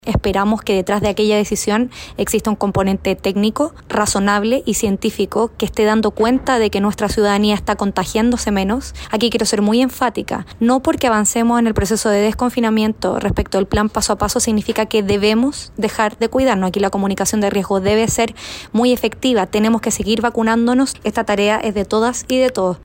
La alcaldesa de Viña del Mar, Macarena Ripamonti, dijo que no hay que relajar las medidas de autocuidado y el proceso de vacunación.